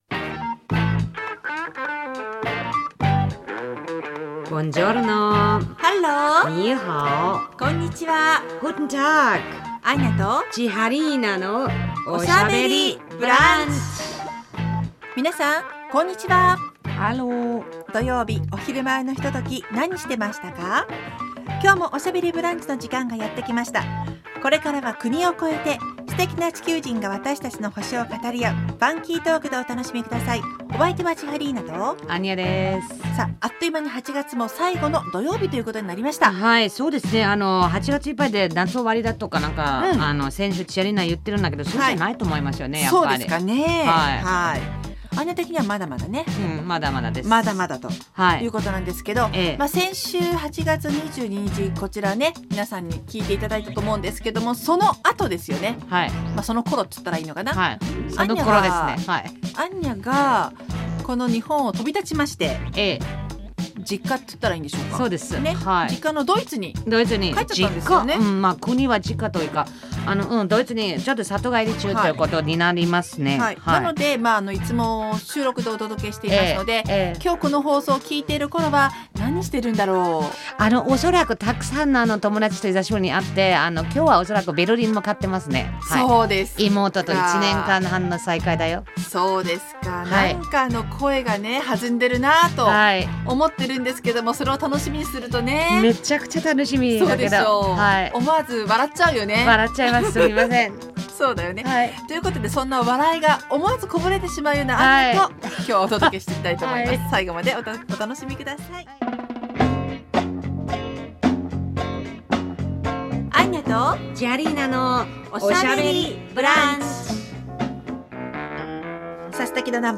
放送された内容を一部編集してお送りします。